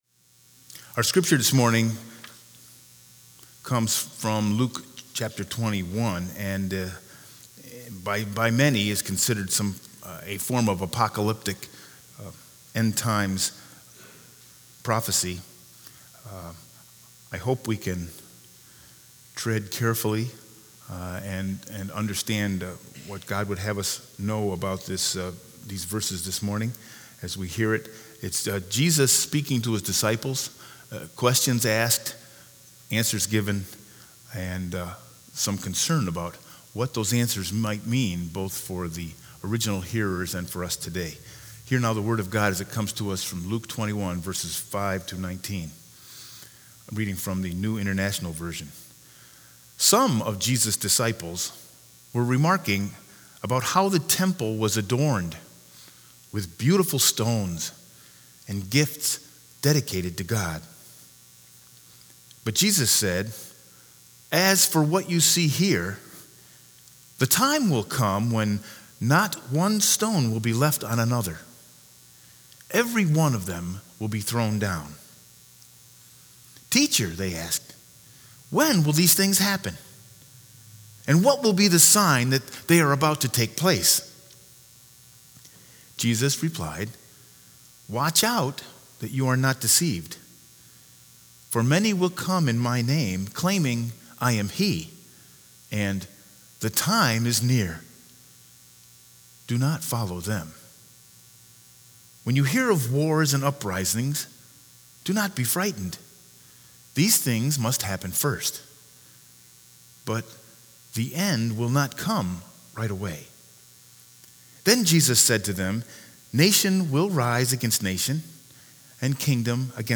Sermon 11-17-19 with Scripture Lesson Luke 21_5-19